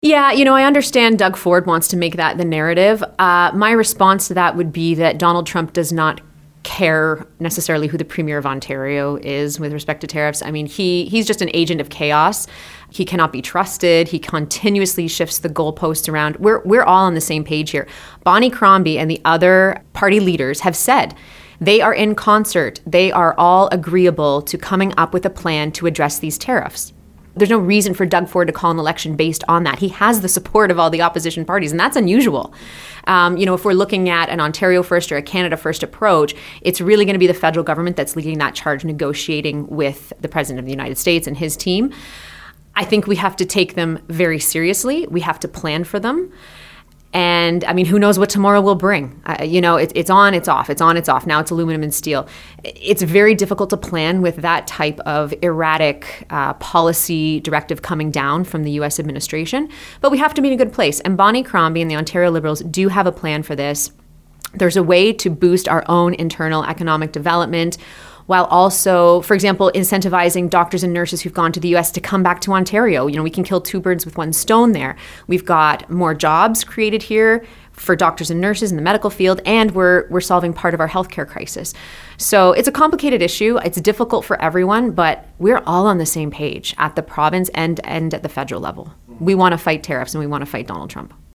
She chose to join us in-person at our Milton studios.
Here’s our interview: